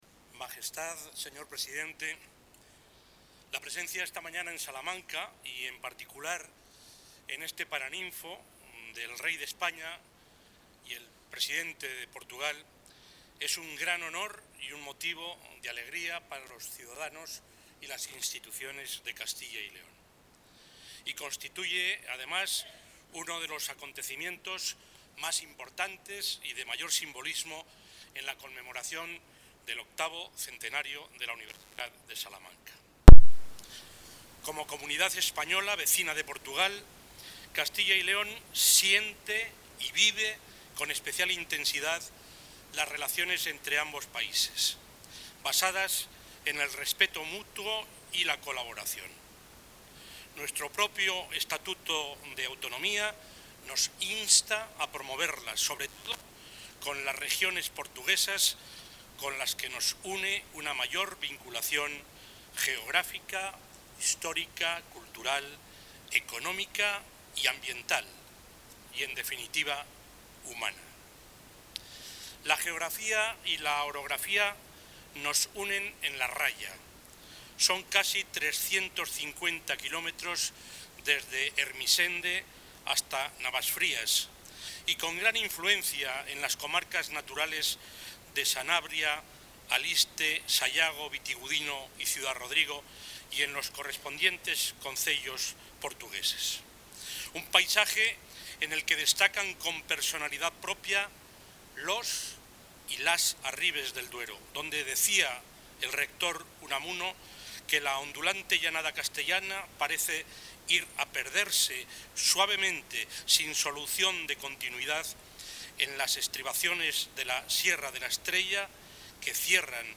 Intervención del presidente de la Junta de Castilla y León.
El presidente de la Junta ha participado hoy en los actos celebrados en Salamanca con motivo de la visita de Estado que el presidente de la República Portuguesa realiza a España, donde ha destacado los vínculos históricos, culturales y económicos que unen a Castilla y León con el país vecino.